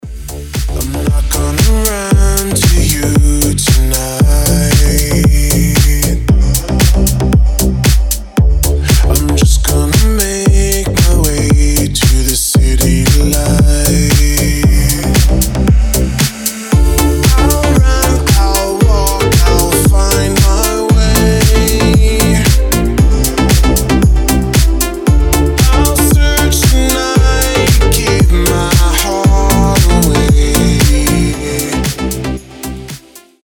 • Качество: 320, Stereo
deep house
красивый мужской голос
мелодичные